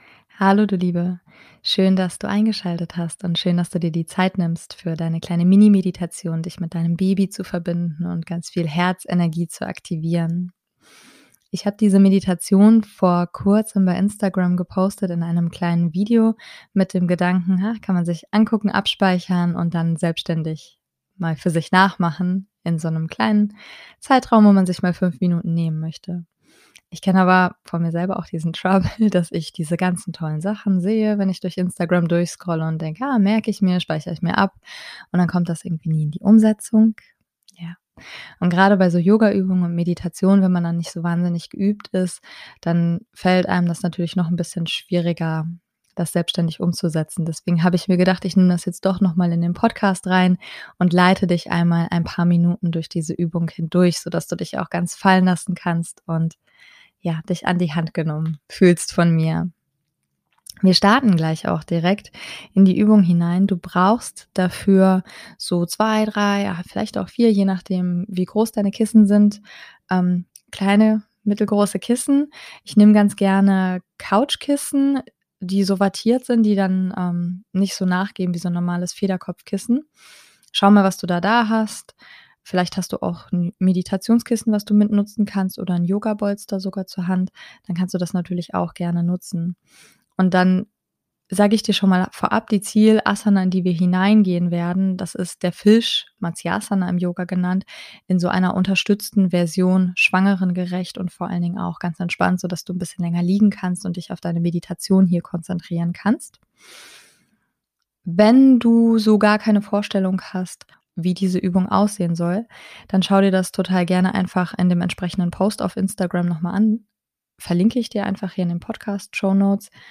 Heute erwartet dich eine Mini Meditation, die ich vor Kurzem schon einmal auf Instagram geteilt habe. Hier jetzt noch einmal mit Live Mitmach Anleitung zum Meditieren für dich.